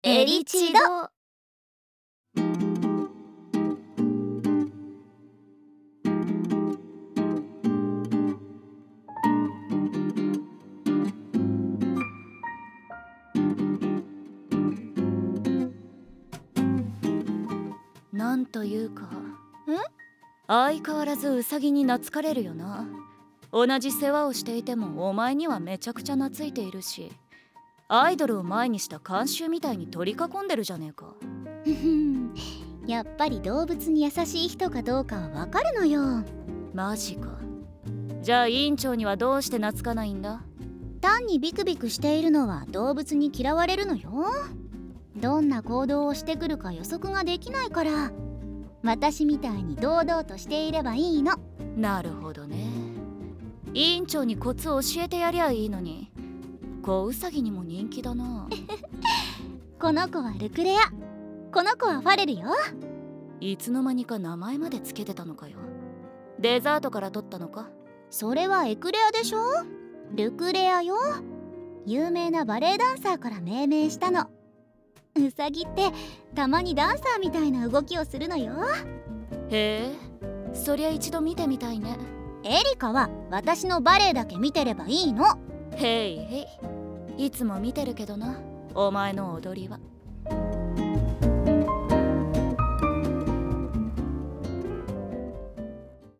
タイトル：FLOWERSオリジナルサウンドドラマ『オクサリスの花言葉』